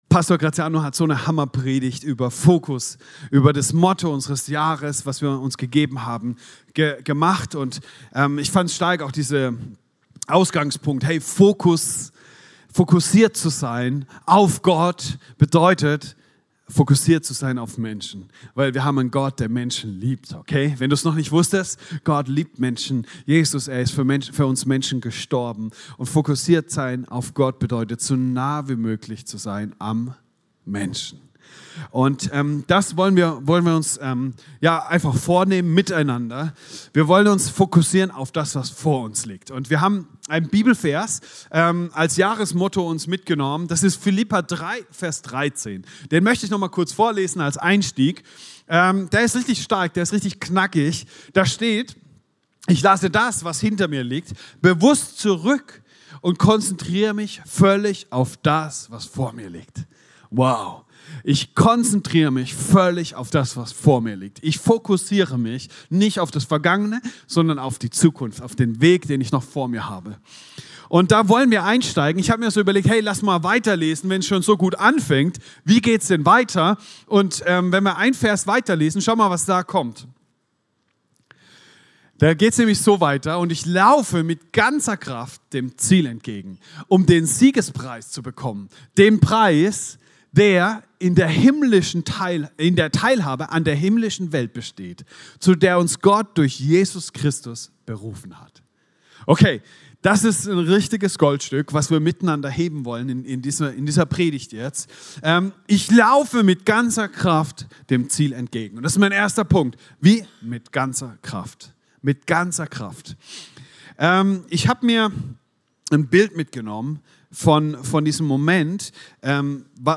Dienstart: Sonntag